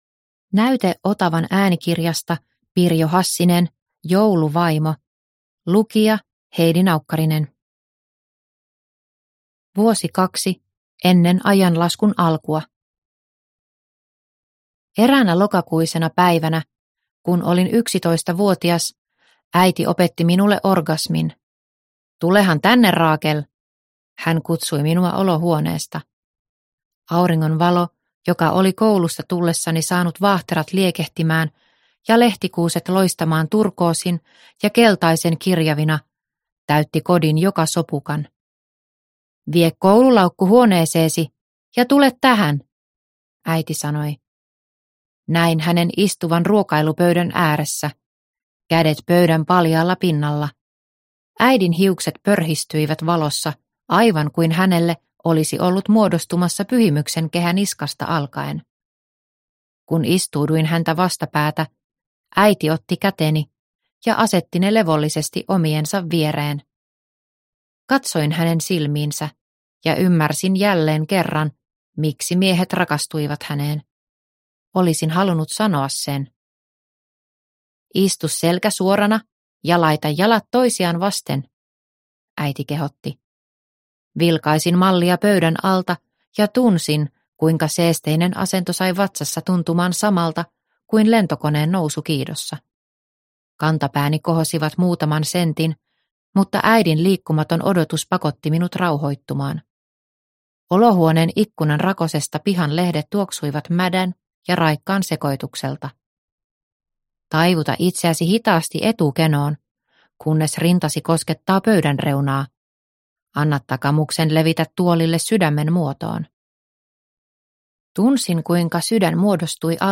Jouluvaimo – Ljudbok – Laddas ner